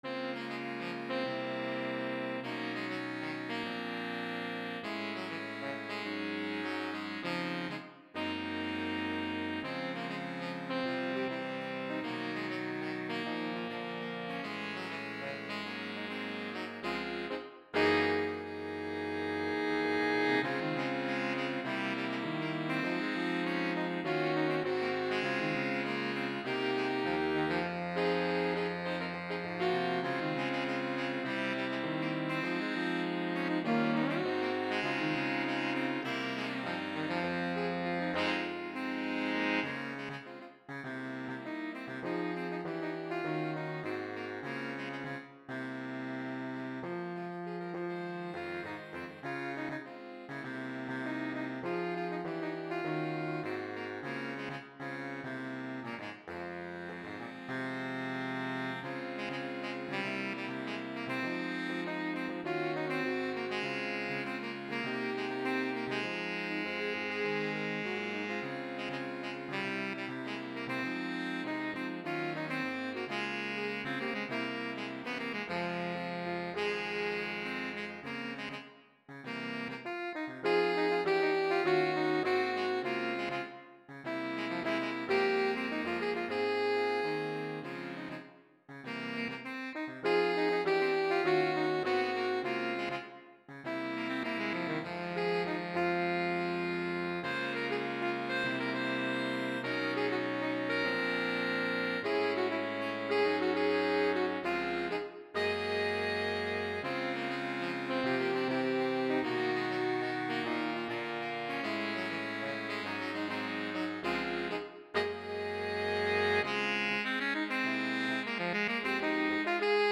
Gattung: für Saxophonquartett oder -quintett
Besetzung: Instrumentalnoten für Saxophon